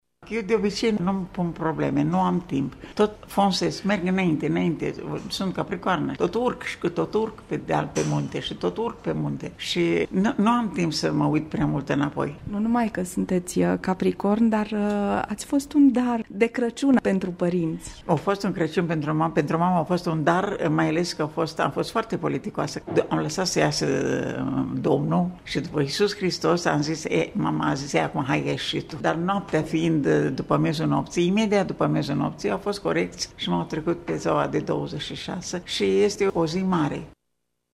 Astăzi, de Crăciun, îi spunem şi noi „La mulţi ani!” artistei lirice Viorica Cortez şi ne bucurăm de prezenţa ei la microfon.